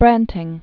(brăntĭng, brän-), Karl Hjalmar 1860-1925.